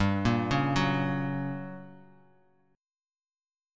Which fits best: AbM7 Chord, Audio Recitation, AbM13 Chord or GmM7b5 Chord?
GmM7b5 Chord